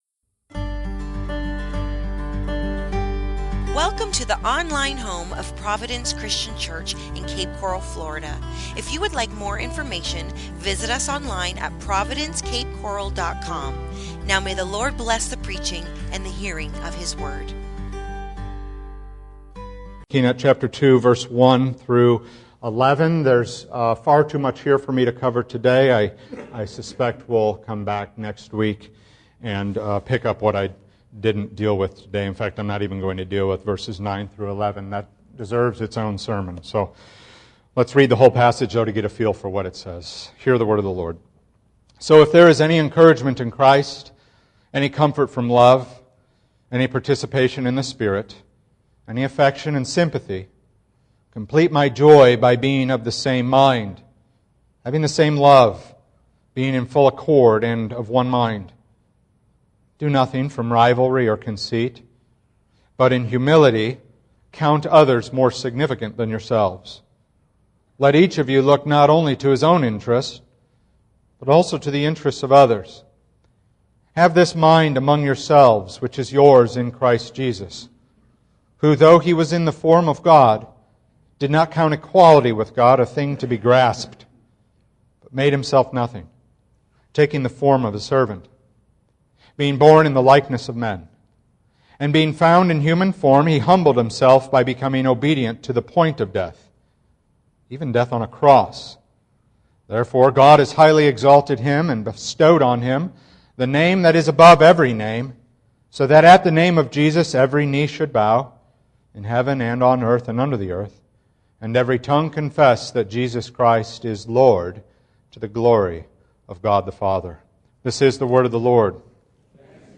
The Mind of Christ | SermonAudio Broadcaster is Live View the Live Stream Share this sermon Disabled by adblocker Copy URL Copied!